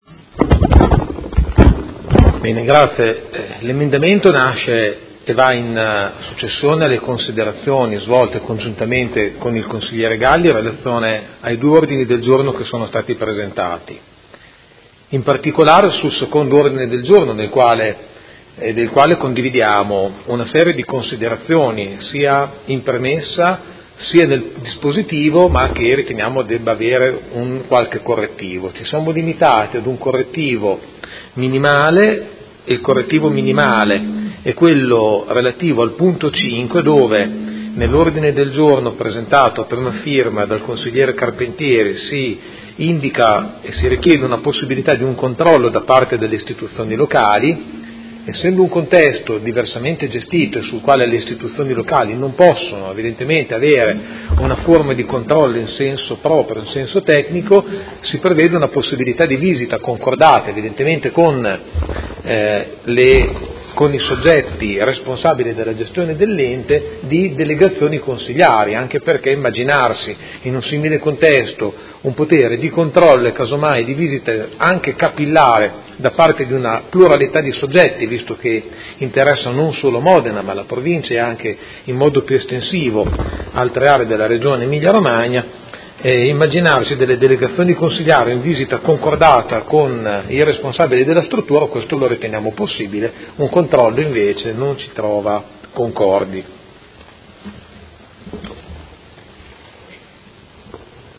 Seduta del 12/04/2018 Dibattito. Ordine del giorno 54394, Emendamento 54490 e ordine del giorno 54480 sulla sicurezza.
Audio Consiglio Comunale